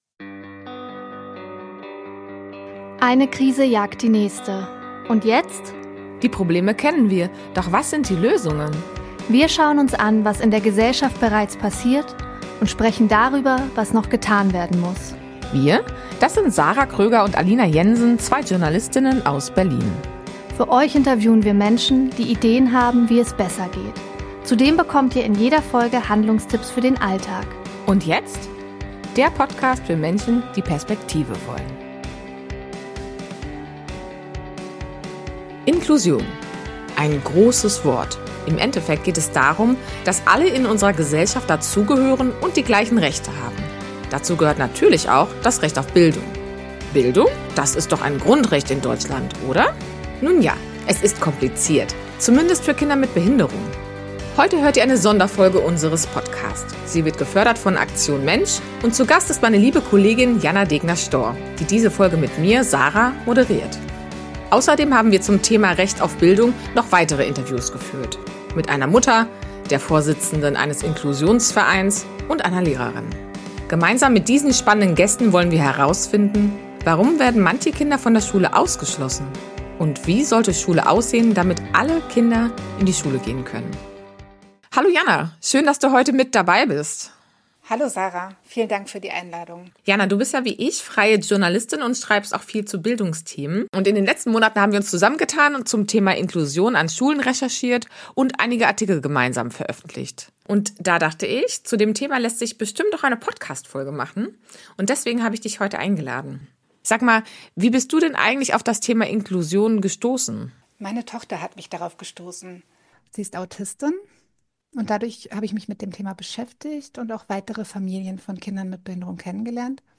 Mit einer Mutter, der Vorsitzenden eines Inklusionsvereins und einer Lehrerin. Gemeinsam mit diesen spannenden Gästen wollen wir herausfinden: Warum werden manche Kinder von der Schule ausgeschlossen?